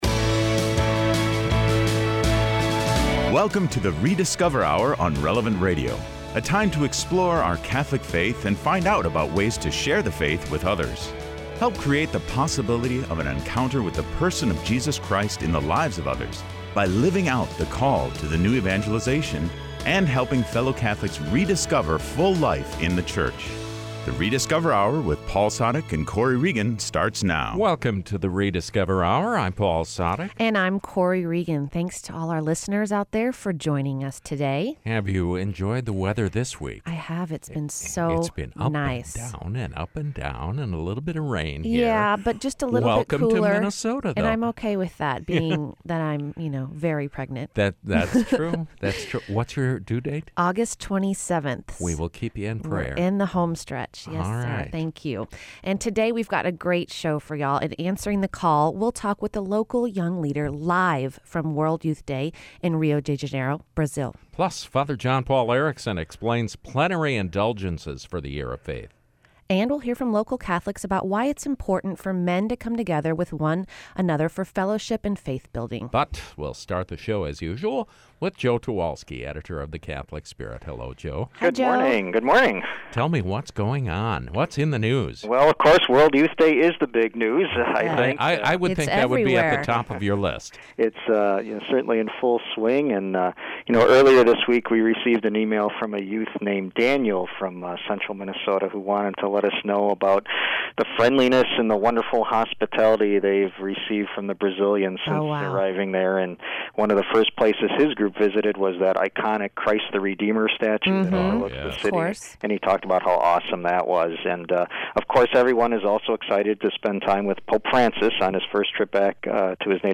Además, escucharemos a hombres católicos locales hablar sobre por qué es importante que los hombres se reúnan en comunidad para crecer en la fe.